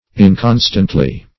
inconstantly - definition of inconstantly - synonyms, pronunciation, spelling from Free Dictionary Search Result for " inconstantly" : The Collaborative International Dictionary of English v.0.48: Inconstantly \In*con"stant*ly\, adv.